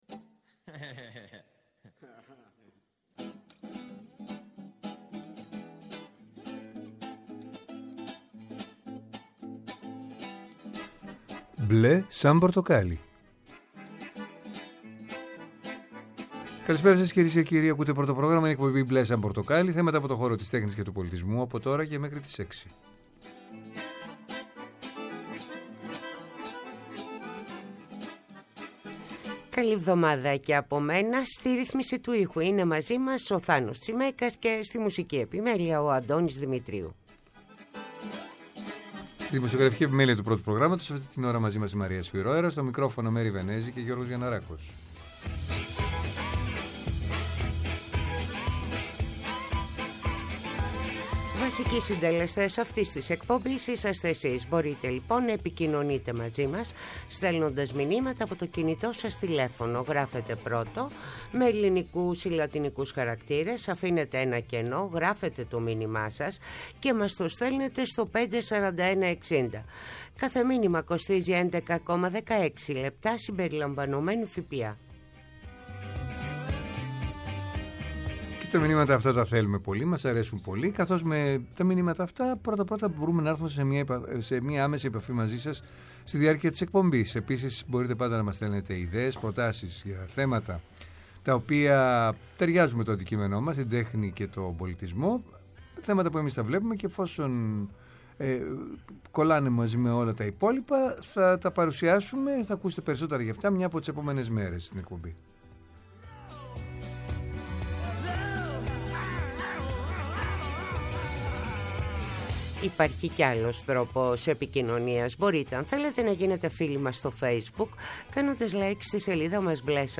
Καλεσμένοι τηλεφωνικά στην εκπομπή: